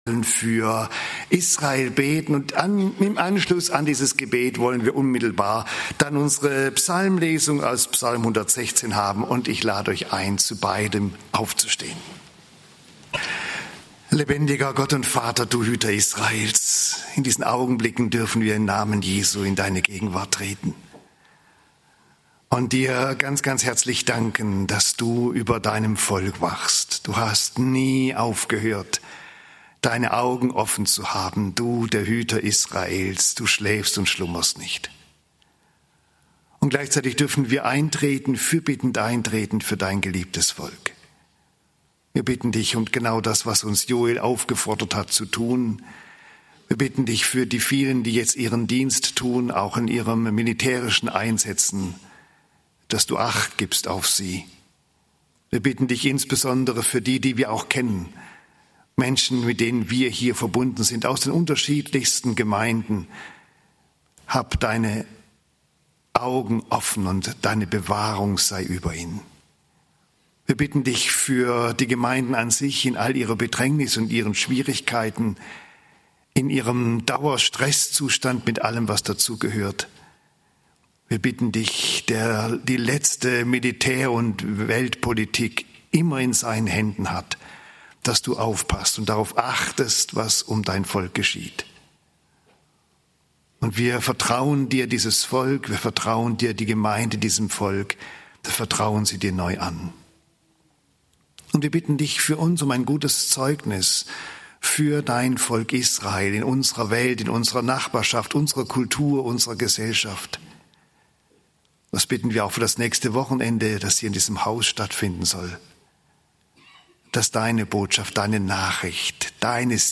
Die Korinther und die Auferstehung - Teil 3 (1. Kor. 15, 35-58) - Gottesdienst